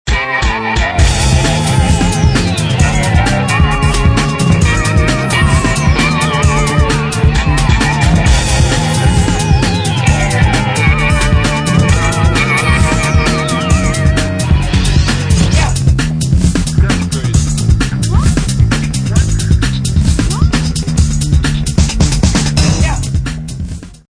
<119k>   イケイケなスパイ系、ウルトラQ、X-FILE、刑事モノ・・・
迫り来る恐怖やスピード感。
Bass&Guitar
Keyboards